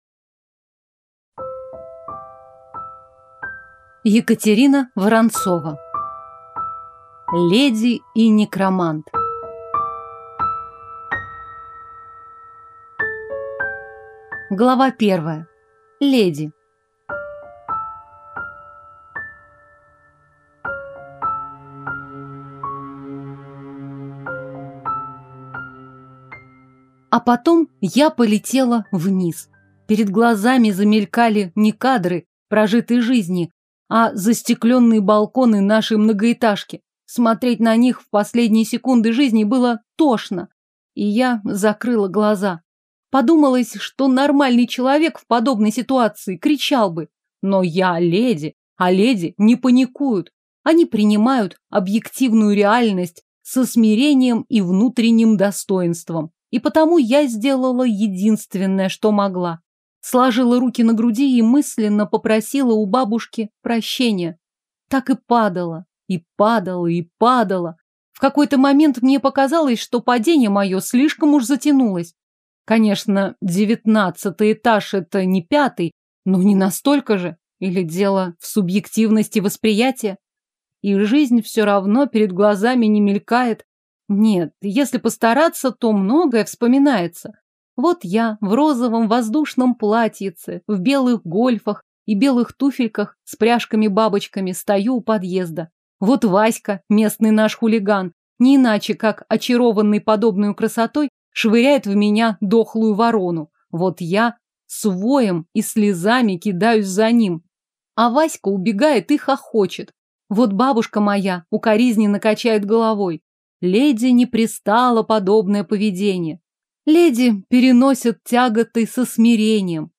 Аудиокнига Леди и Некромант | Библиотека аудиокниг